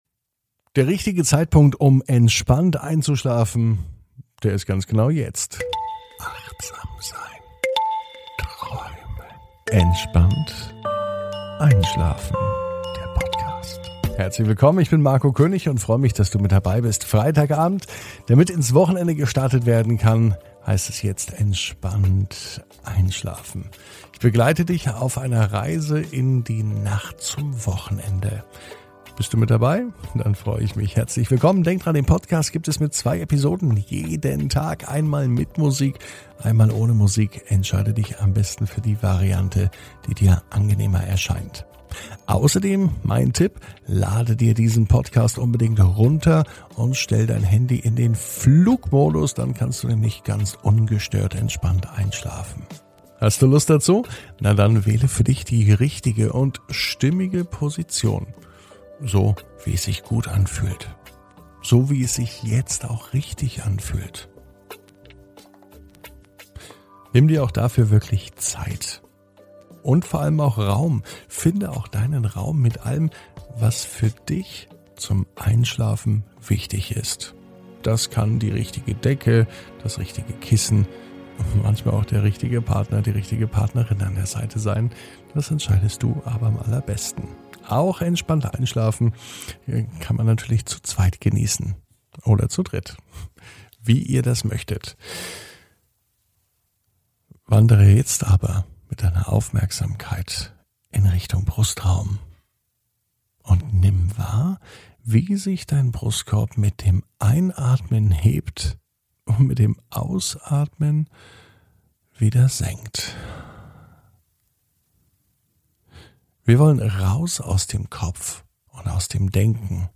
(Ohne Musik) Entspannt einschlafen am Freitag, 21.05.21 ~ Entspannt einschlafen - Meditation & Achtsamkeit für die Nacht Podcast